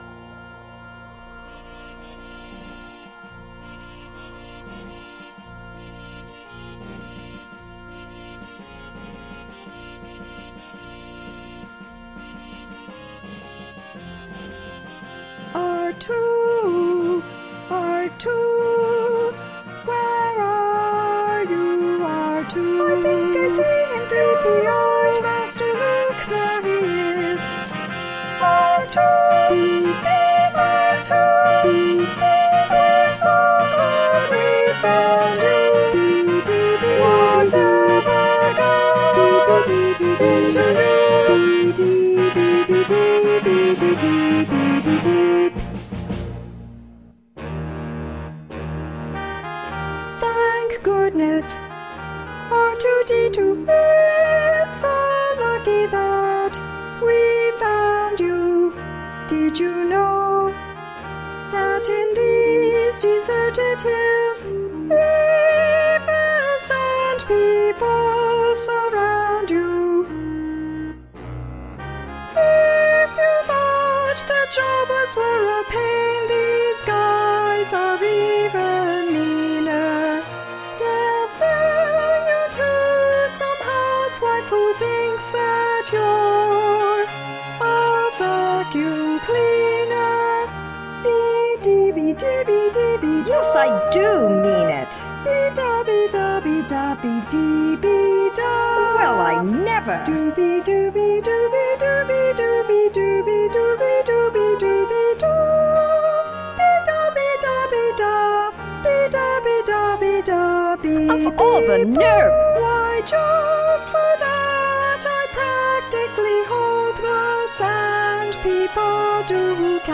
Demo / Orchestra)